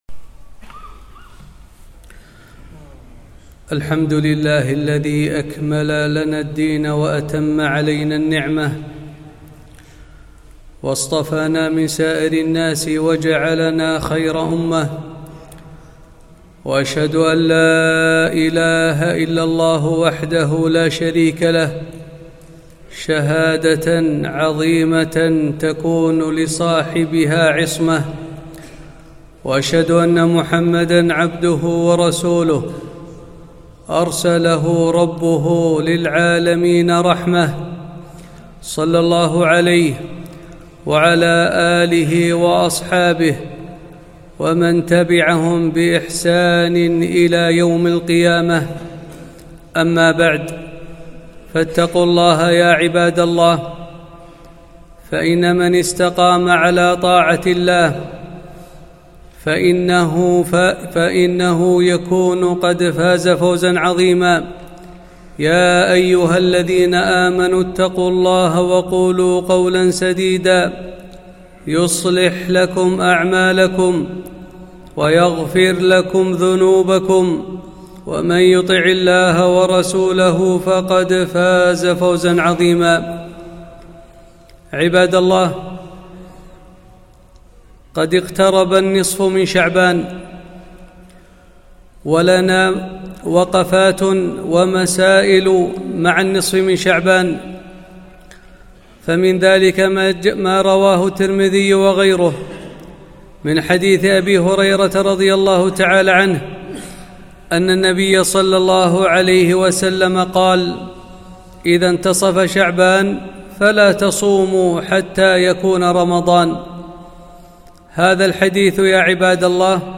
خطبة - وقفات مع قرب انتصاف شعبان